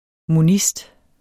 Udtale [ moˈnisd ]